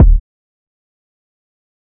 WG Kick.wav